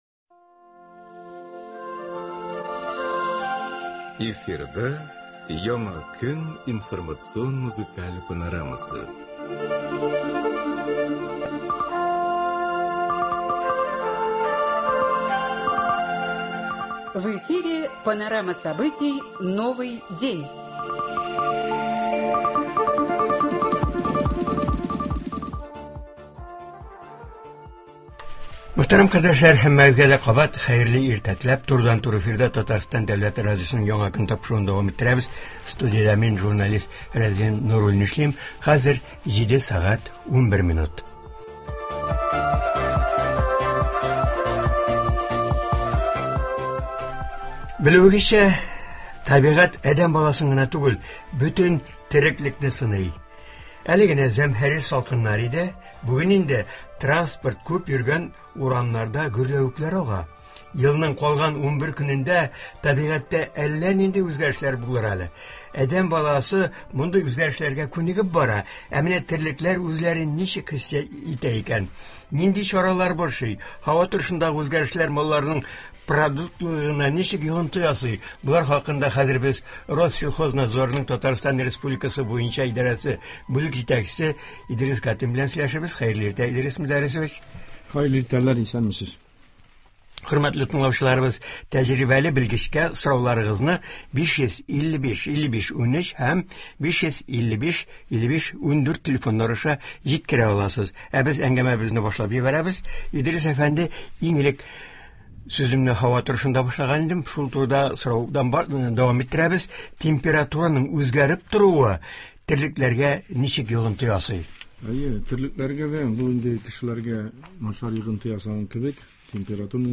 Туры эфир (20.12.23)
Киләсе 2024 нче ел илебездә Гаилә елы буларак игълан ителгәнлектән, Татарстан дәүләт Архив идарәсе дә төрле чаралар, шәҗәрә бәйрәмнәре үткәрәчәк. Болар хакында турыдан-туры эфирда Татарстан республикасының дәүләт Архив идарәсе җитәкчесе Гөлнара Габдрахманова сөйли, тыңлаучыларны кызыксындырган сорауларга җавап бирә.